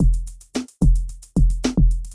踢球 " 踢球 低音提琴 Wakdorf Q Reverb 3
描述：由Waldorf Q.合成的带混响的踢球时的低音声音
标签： 嘻哈 回响贝斯 低音鼓 华尔 子低音 配音 低音鼓
声道立体声